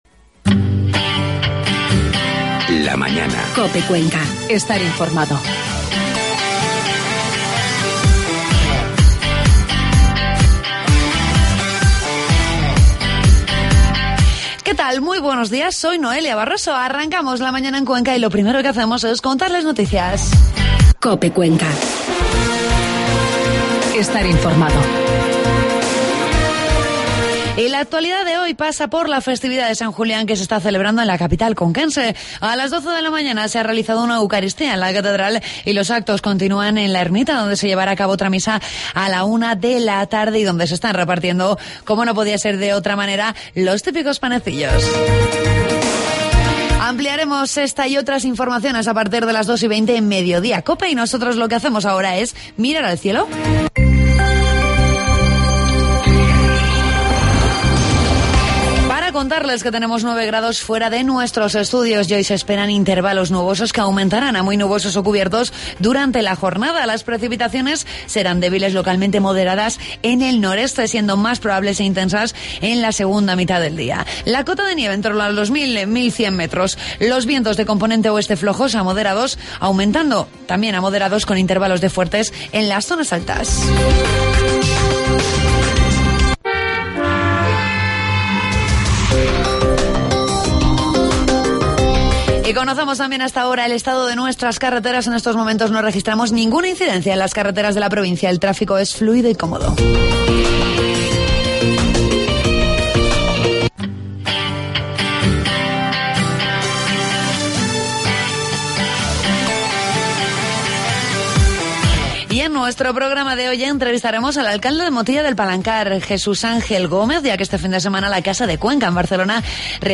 Entrevistamos al alcalde de Motilla, Jesús Ángel Gómez, con motivo de la jornada que se realizó en la casa de Cuenca en Barcelona el pasado fin de semana.